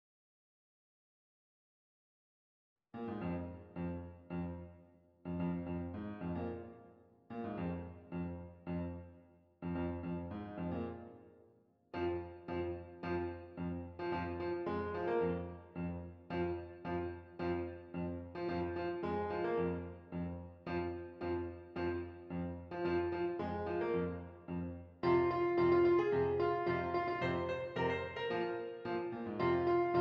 F Minor
Moderate Ballad